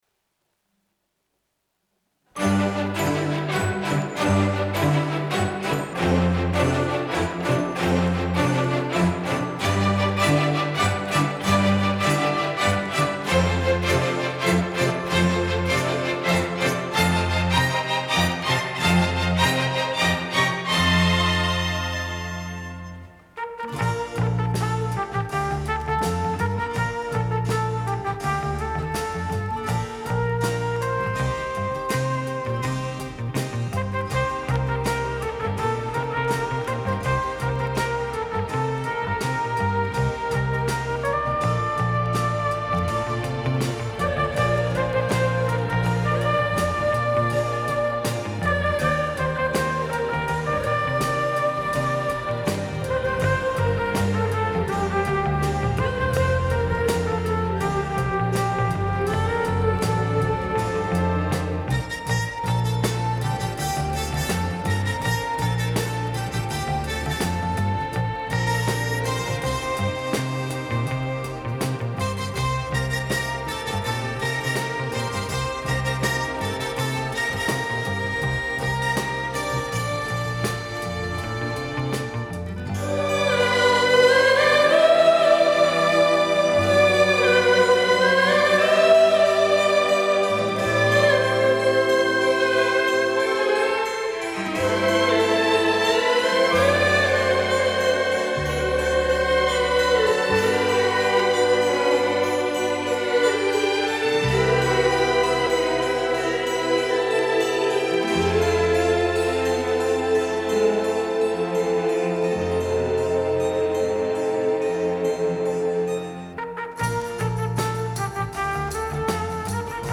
Формат:Vinyl, LP, Stereo
Жанр:Jazz, Pop, Classical, Stage & Screen
Стиль:Easy Listening, Theme